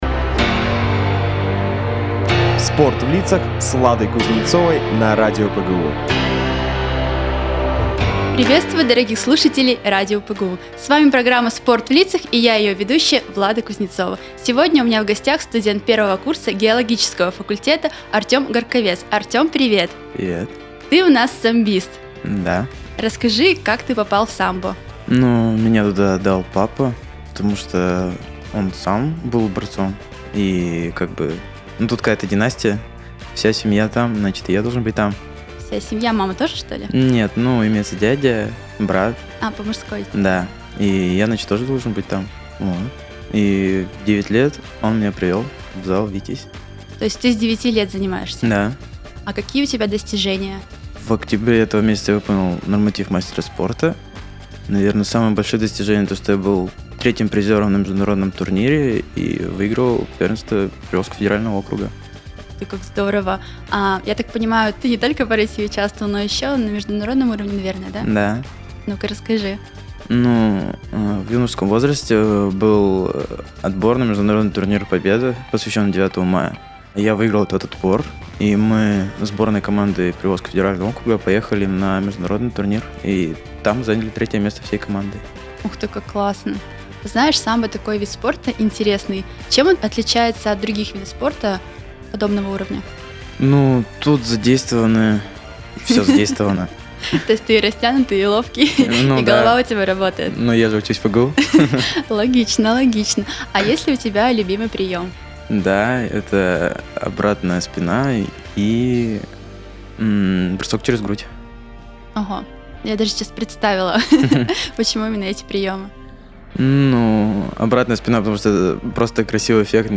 В студии Радио ПГУ